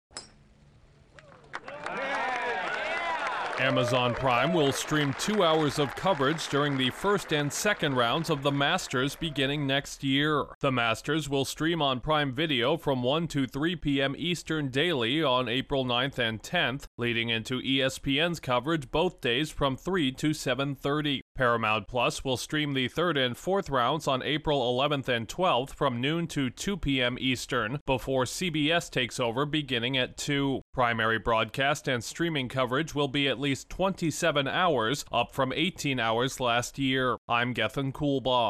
Golf's biggest stateside major will be viewable for more hours on a new platform. Correspondent